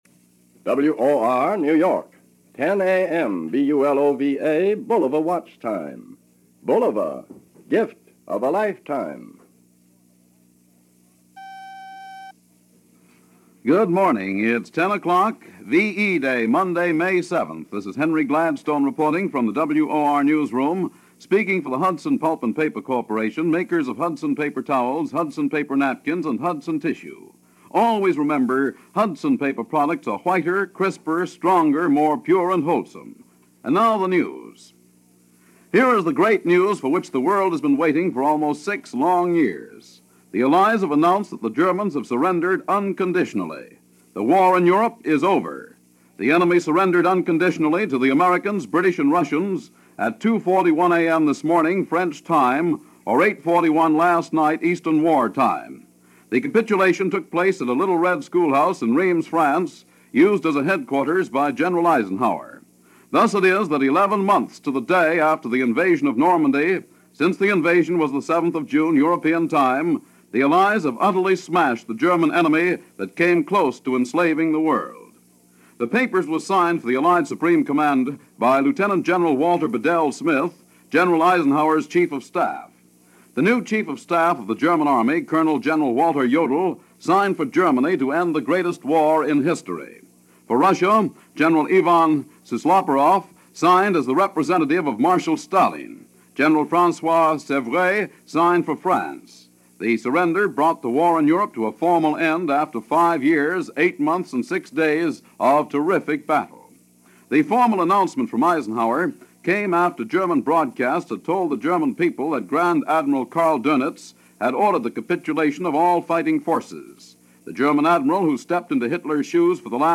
VE Day - One Down - One To Go - May 7, 1945 - news from Mutual/WOR Radio in New York - 10:00 am - 11:00 EWT.